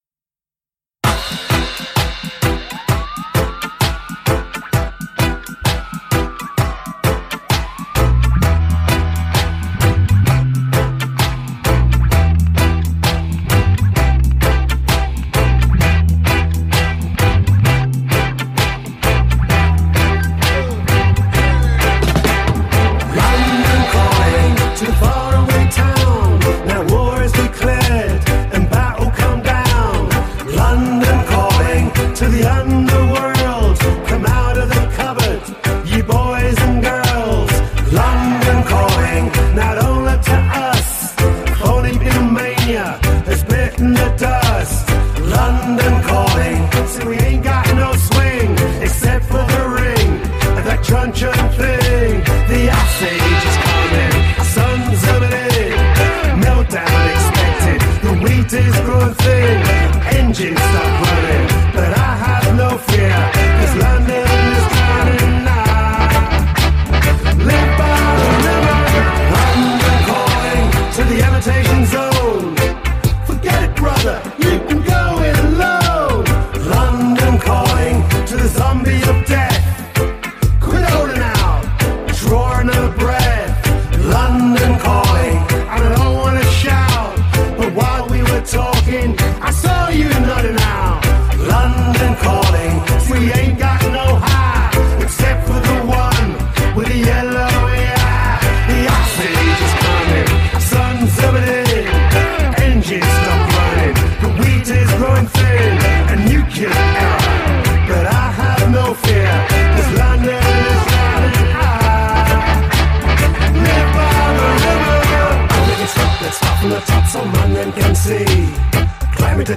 Roots Rock version